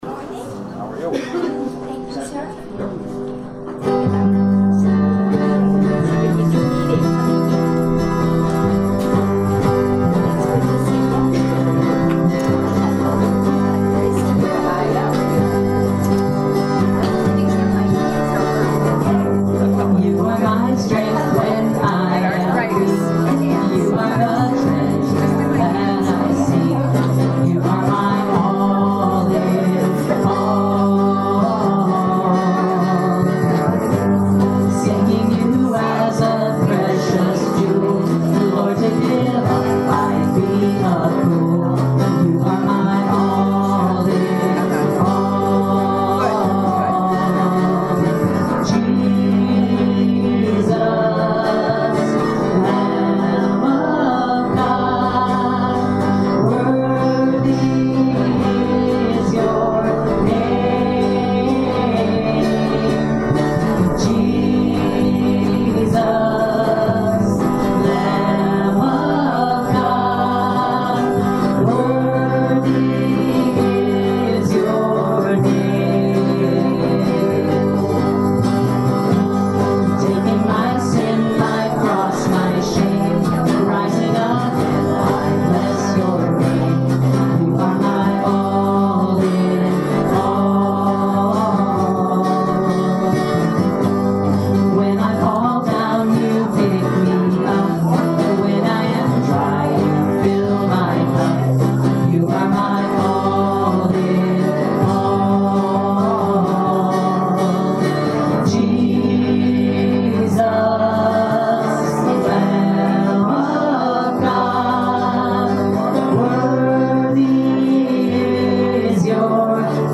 Hymn of Joy: #542 Near to the Heart of God
Benediction & Choral Amen